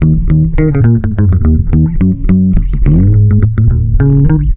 RI BASS 1 -L.wav